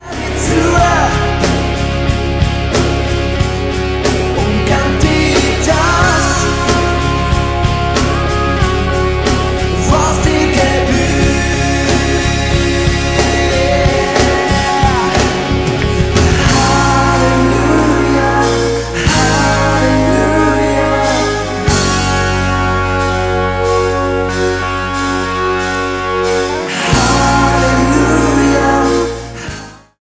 Diese Worship-CD wurde am Crea Meeting live aufgenommen.